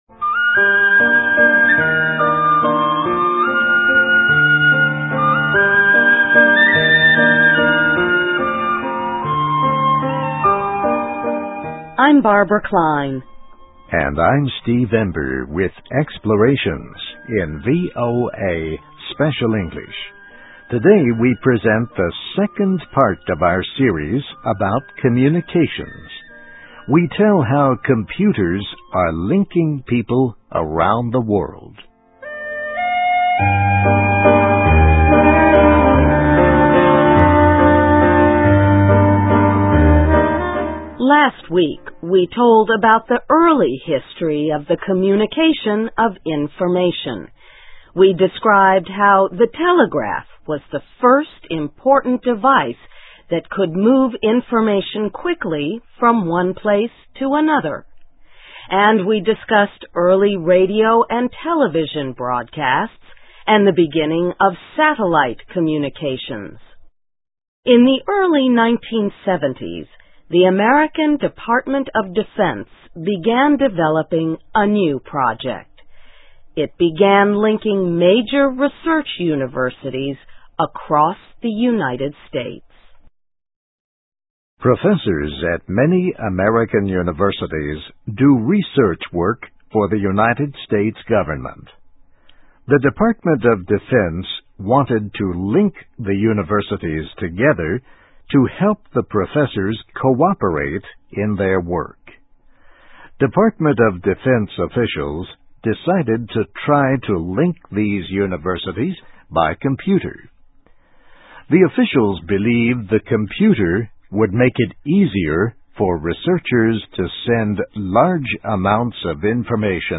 ESL, EFL, English Listening Practice, Reading Practice